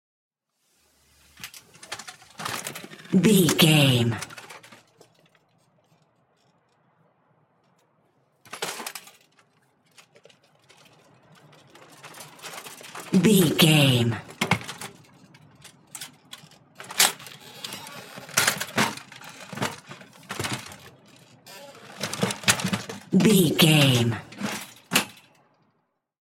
Ambulance Stretcher Litter In Out
Sound Effects
urban
chaotic
emergency